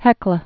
(hĕklə)